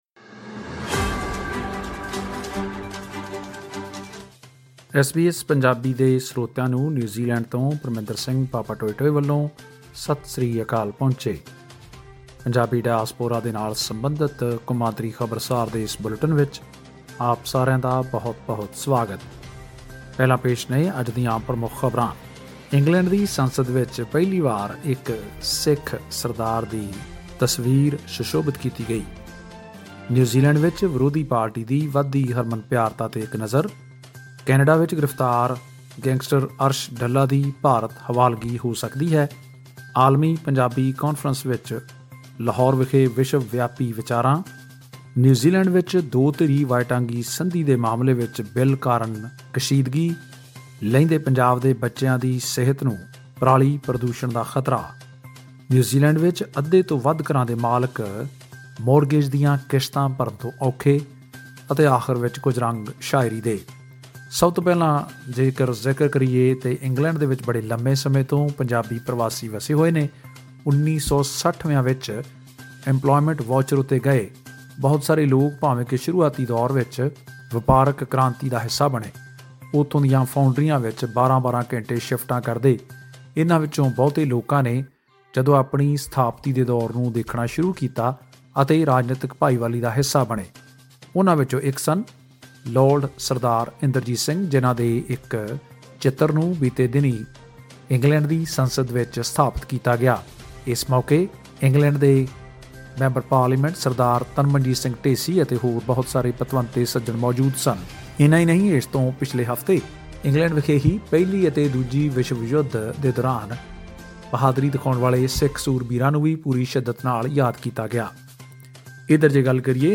ਇੰਗਲੈਂਡ ਦੀ ਸੰਸਦ ਦੇ 'ਬਿਸ਼ਪ ਕੋਰੀਡੋਰ ਆਫ ਹਾਊਸ ਆਫ ਲੋਰਡਜ਼' ਵਿੱਚ ਇੰਗਲੈਂਡ ਅਤੇ ਯੂਰੋਪ ਦੇ ਪਹਿਲੇ ਪੱਗੜੀਧਾਰੀ ਸਿੱਖ ਸੰਸਦ ਮੈਂਬਰ ਲੋਰਡ ਡਾ. ਇੰਦਰਜੀਤ ਸਿੰਘ ਦੀ ਤਸਵੀਰ ਲਗਾਈ ਗਈ। ਵਿਦੇਸ਼ਾਂ ਵਿੱਚ ਵੱਸਦੇ ਪੰਜਾਬੀਆਂ ਦੀਆਂ ਪ੍ਰਾਪਤੀਆਂ ਅਤੇ ਖ਼ਬਰਾਂ ਬਾਰੇ ਹੋਰ ਜਾਣਕਾਰੀ ਲਈ ਸੁਣੋ ਇਹ ਖਾਸ ਰਿਪੋਰਟ।